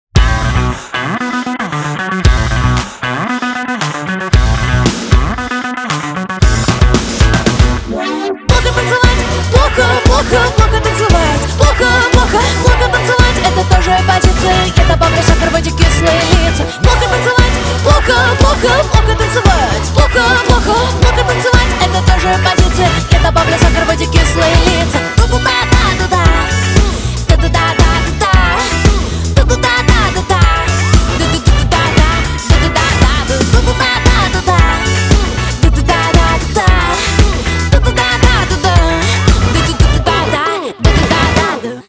• Качество: 320, Stereo
поп
женский вокал
зажигательные
веселые